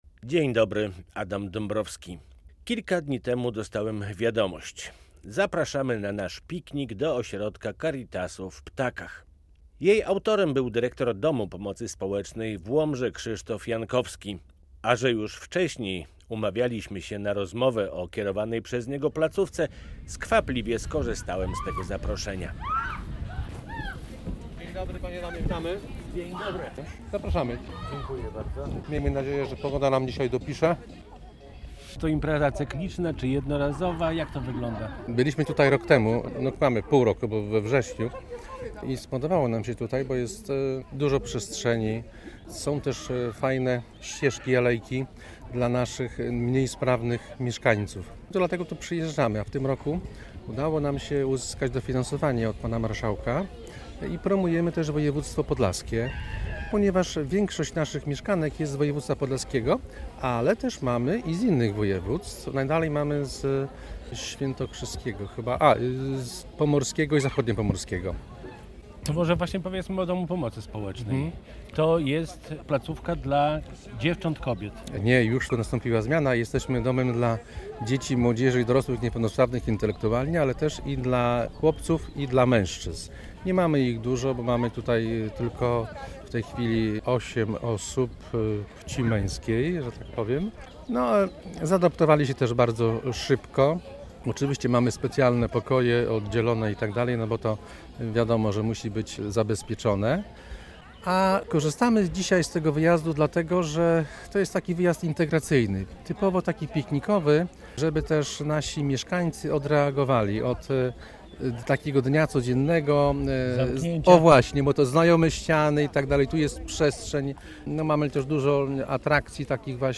Piknik DPS w Ośrodku "Caritas" w Ptakach